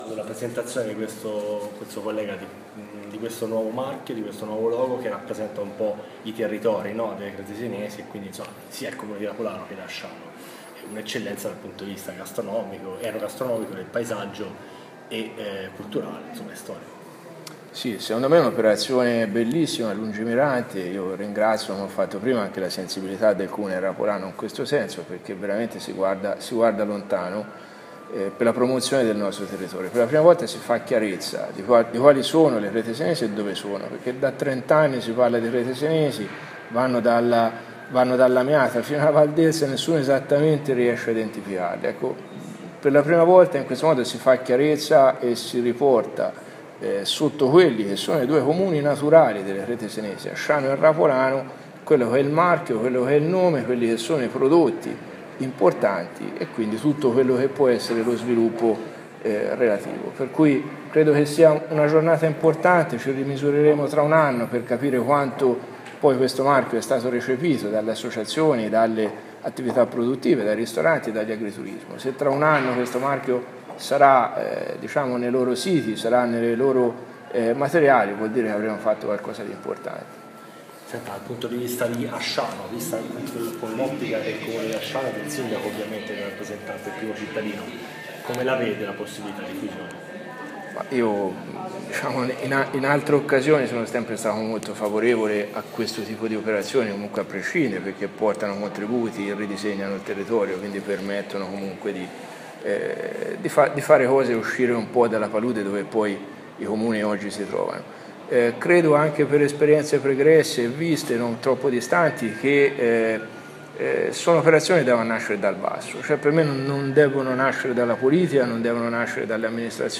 Paolo Bonari sindaco di Asciano